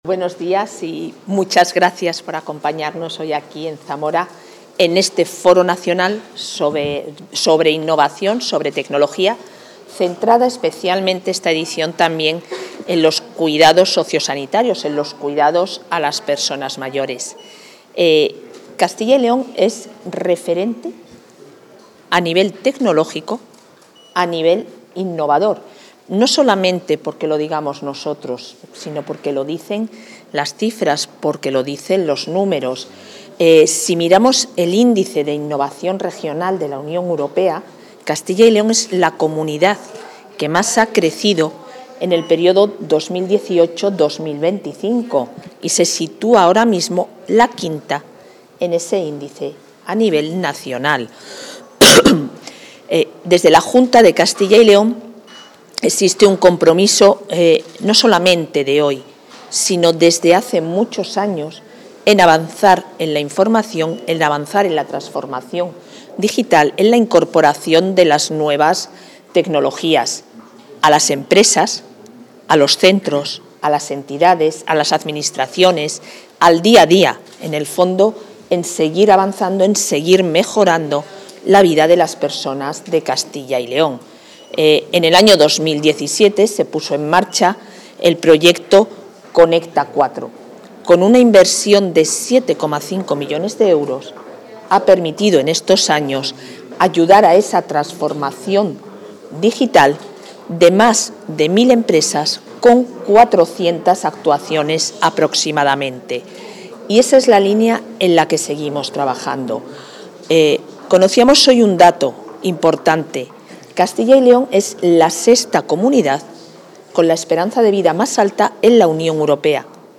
Intervención de la vicepresidenta.
La vicepresidenta y consejera de Familia e Igualdad de Oportunidades inaugura el Foro nacional ‘Salud y bienestar. Innovación saludable: Ciencia, tecnología y personas’ del Instituto de Competitividad Empresarial de Castilla y León, donde ha defendido la conexión entre rentabilidad empresarial y responsabilidad social a través de la innovación saludable.